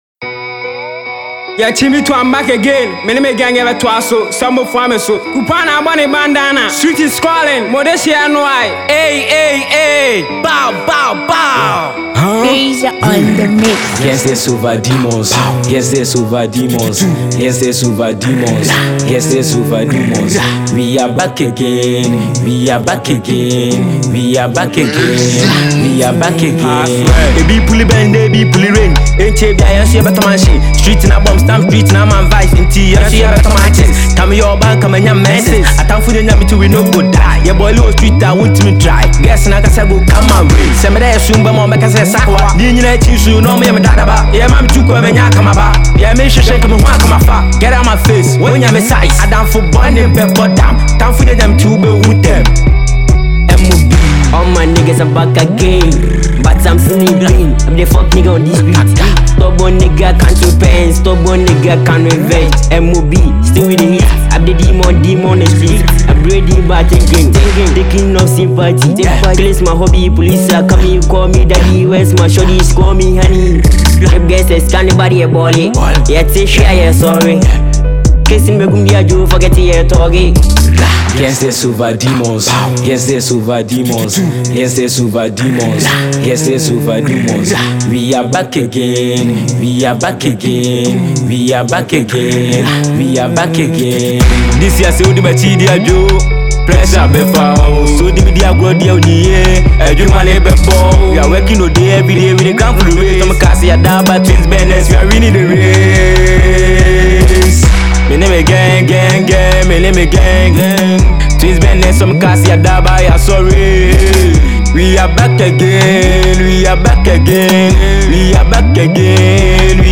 Listen up and download this lovely Ghana drill song below.